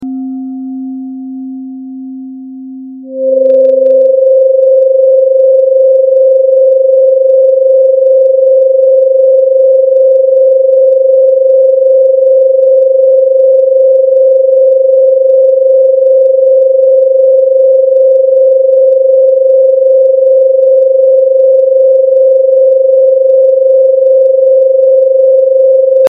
Your Solar Plexus holds your power. When it’s blocked, you feel stuck. 528Hz helps you clear it — and reclaim your confidence.